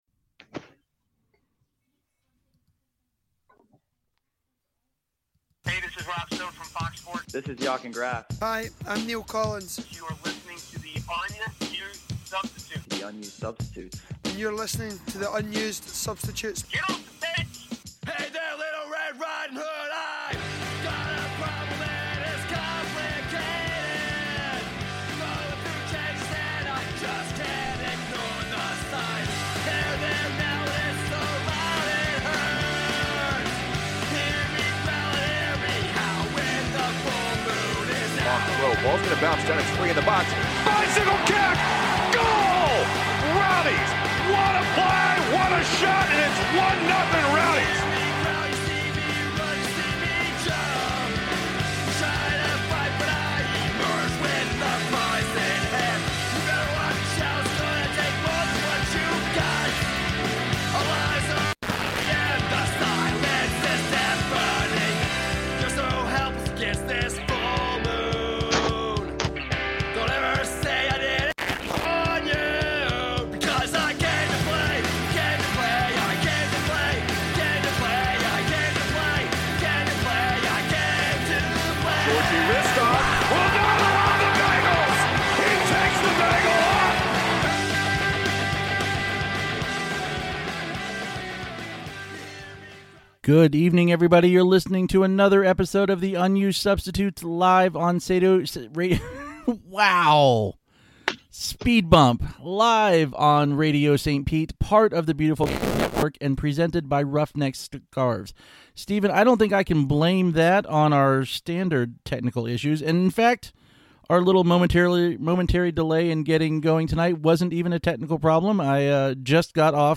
n independent, supporter-created podcast delivering news, interviews and opinions about the Tampa Bay Rowdies soccer club, playing in the USL. Airs live on Radio St. Pete and a member of the Beautiful Game Network.